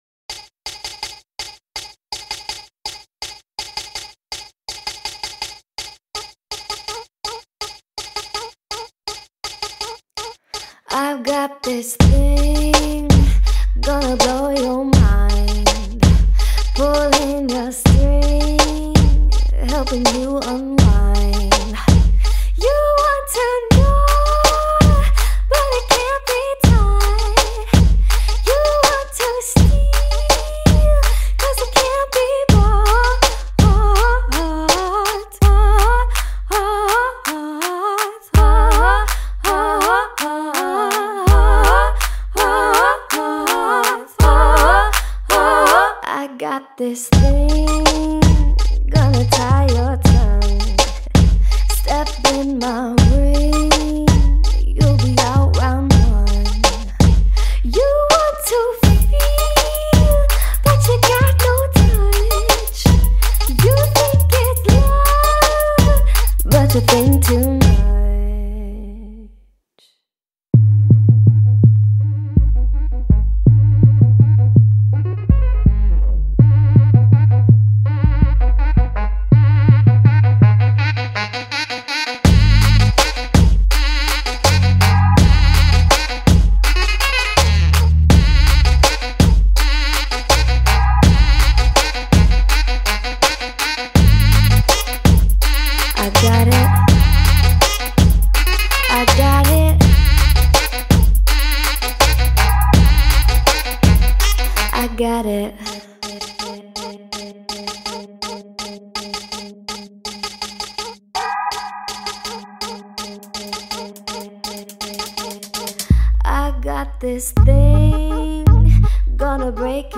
3. The horniest saxophone in the history of music.